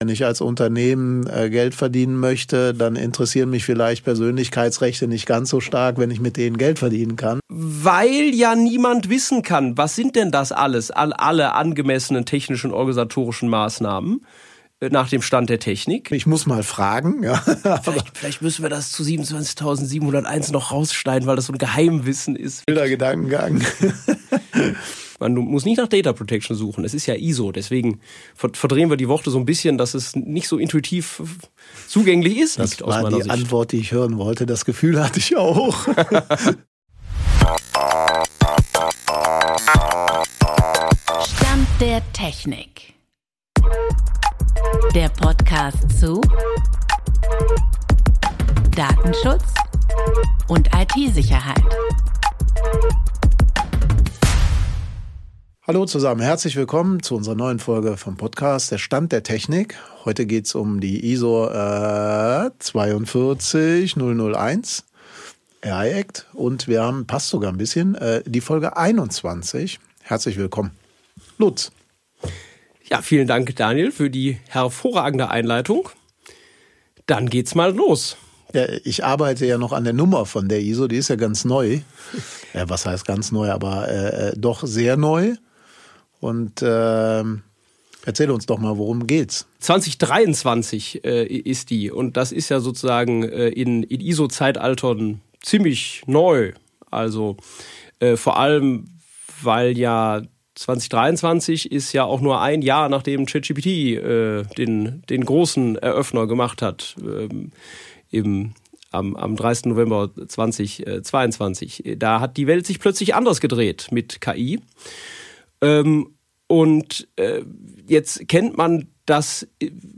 Produktion: Foundation Room Studio